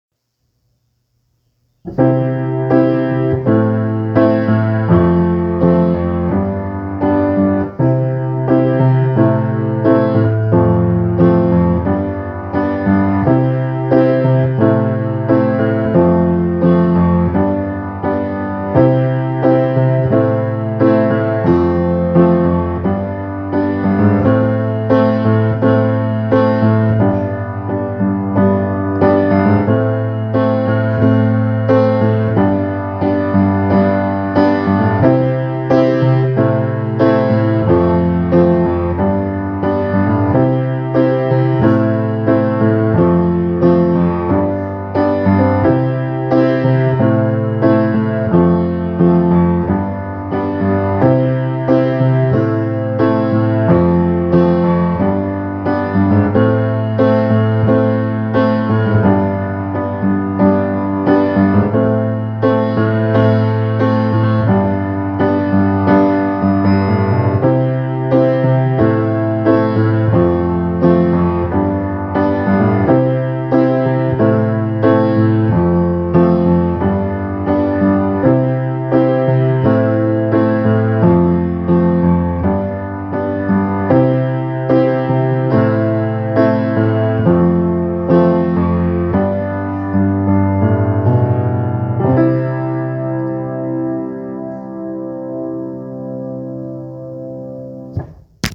Click the mp3 link below and listen to a short piano piece. Once you’ve listened to it a couple of times, try to write lyrics and/or a melody to go along with it!